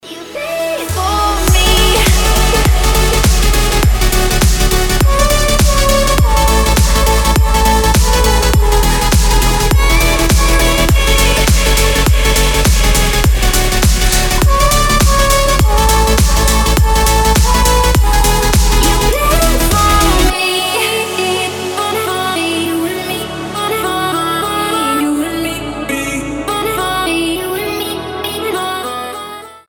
• Качество: 320, Stereo
громкие
dance
Electronic
EDM
энергичные
progressive house